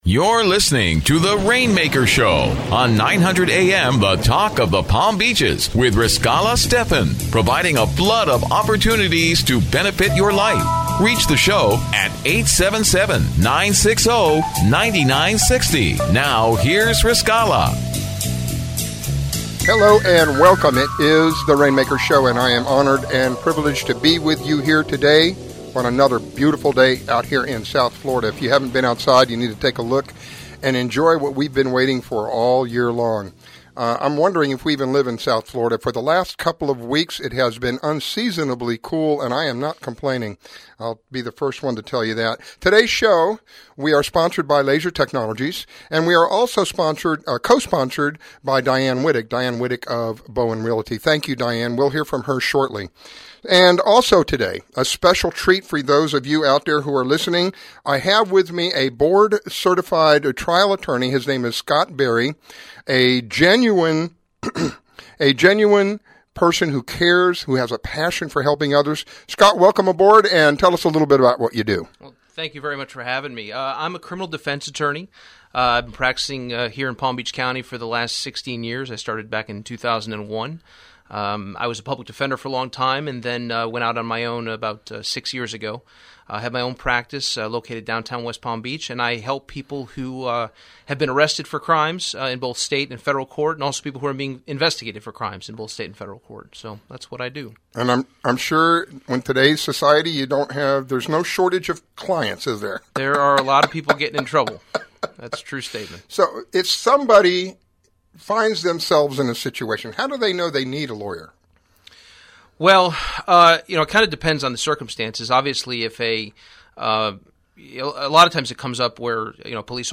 Interviewing entrepreneurs and business owners that will provide business opportunities to people seeking extra income, financial security, self-employment benefits and much more!